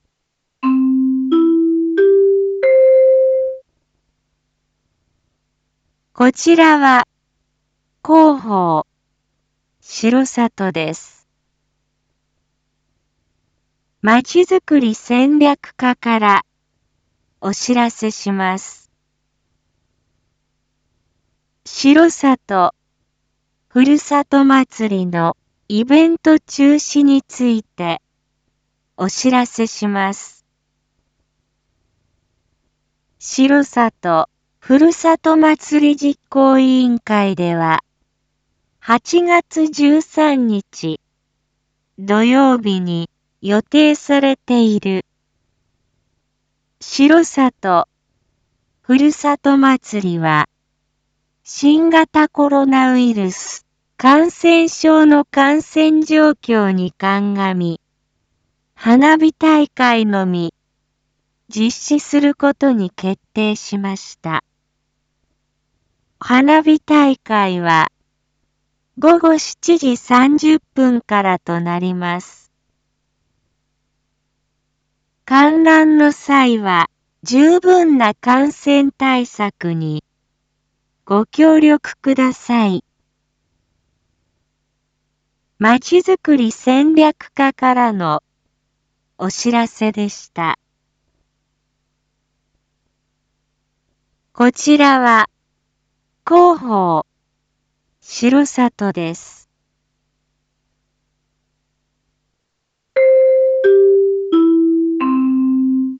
一般放送情報
Back Home 一般放送情報 音声放送 再生 一般放送情報 登録日時：2022-08-11 07:01:47 タイトル：R4.8.11 7時放送分 インフォメーション：こちらは広報しろさとです。